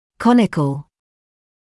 [‘kɔnɪkl][‘коникл]конический, конусный